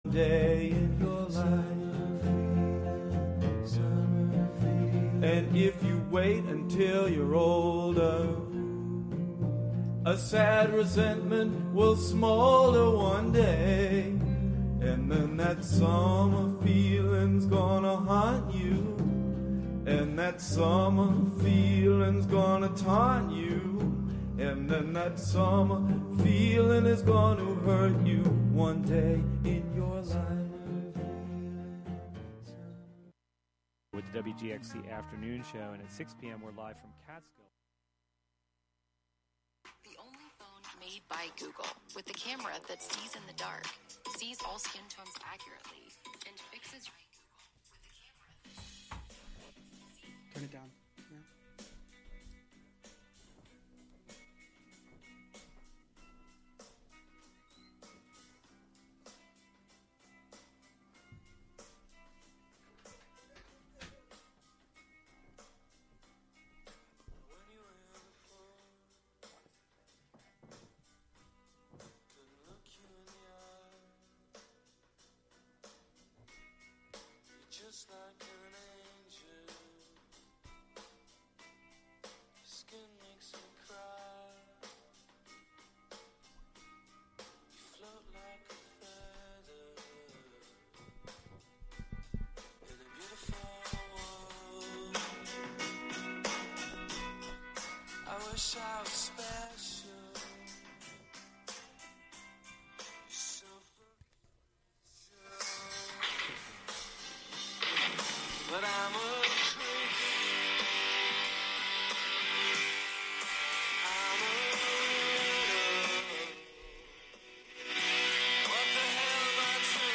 Show includes local WGXC news at beginning, and midway through.